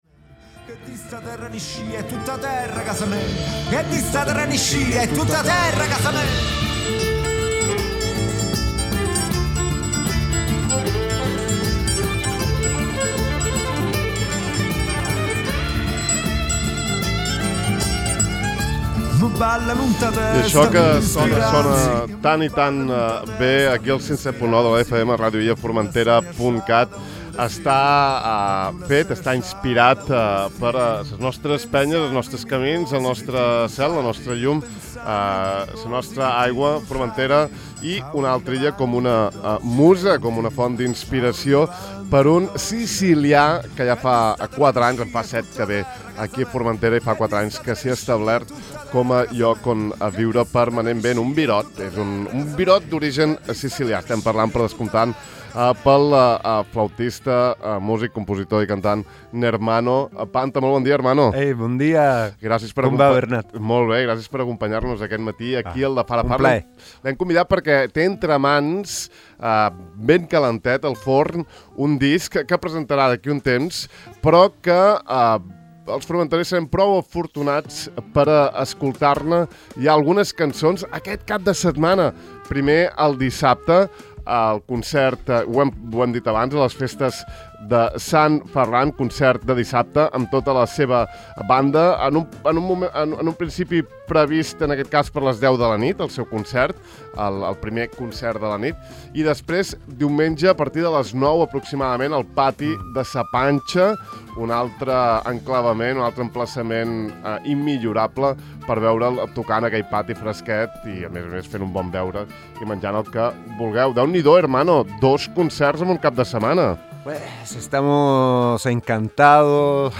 Fins i tot ens han tocat una meravellosa peça en directe. No us perdeu l’entrevista!